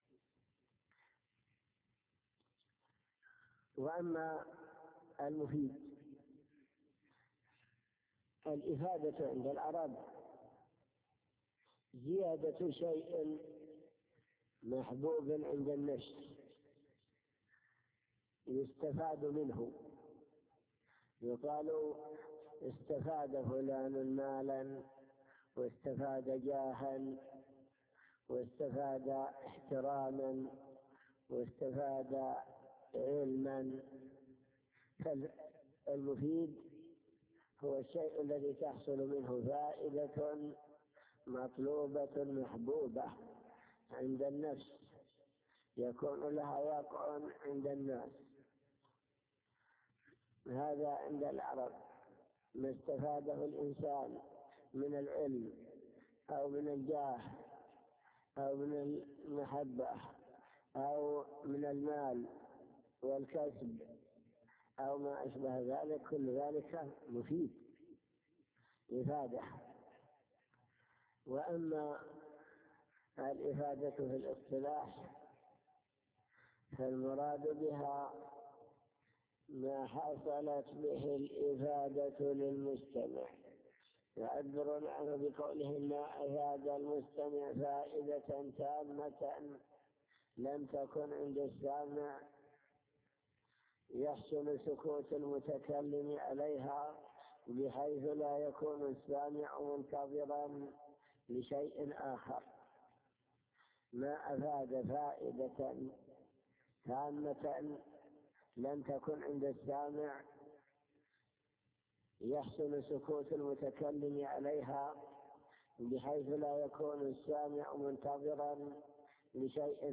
المكتبة الصوتية  تسجيلات - كتب  شرح كتاب الآجرومية